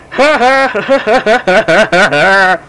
Goofy Laugh Sound Effect
Download a high-quality goofy laugh sound effect.
goofy-laugh-2.mp3